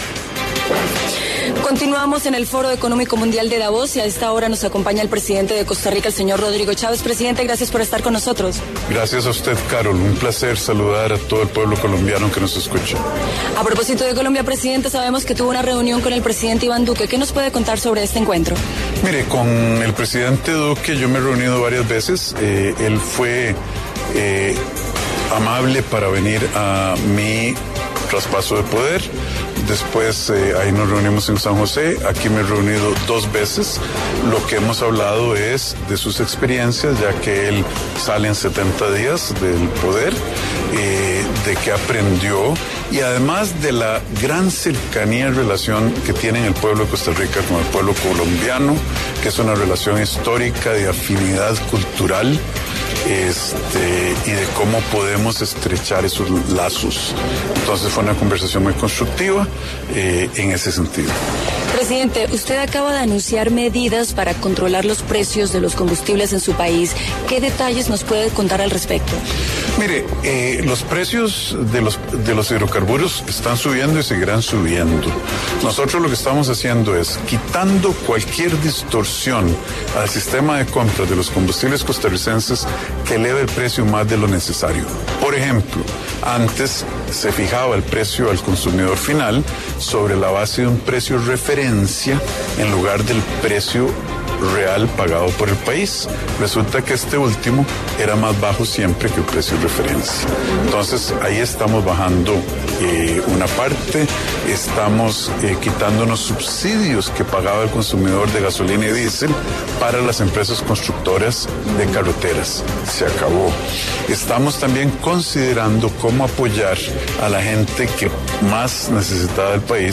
En el encabezado escuche la entrevista completa con Rodrigo Chaves, presidente de Costa Rica, desde el Foro Económico Mundial de Davos.